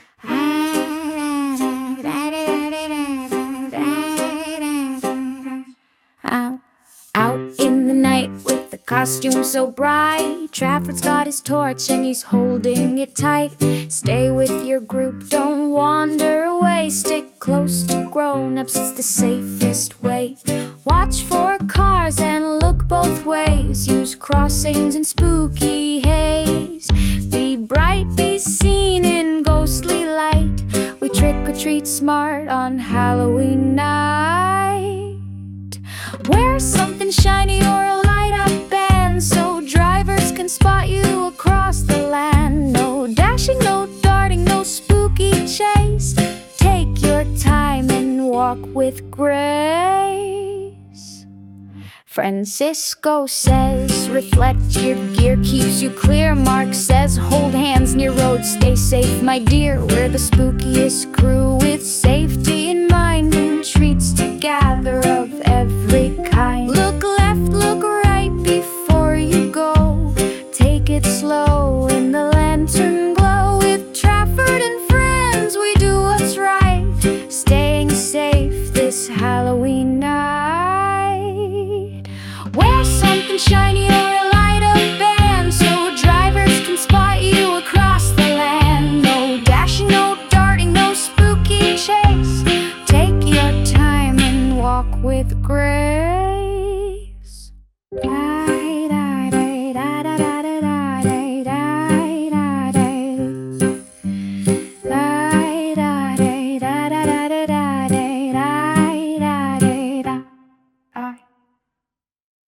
Halloween song
With its catchy chorus: